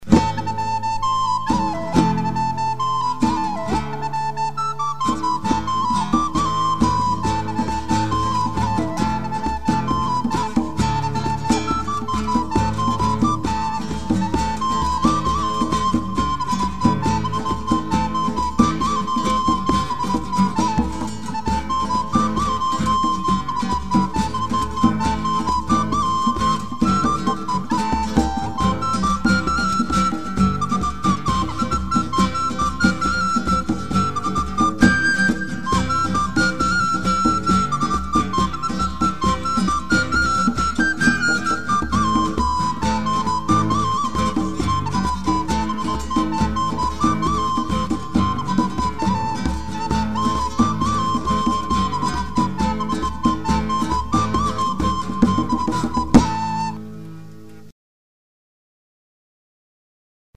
ניגון חב"ד בביצוע קצרצר.
זה בעצם ניסוי של התכנה החדשה (בשבילי) "cool edit".
חלילית, גיטרה וג'מבה - אני.
מעבר לכך, או שלא שמעו מספיק טוב את הג'מבה או שנדרש עוד קצת תיאום בינו לבין הגיטרה.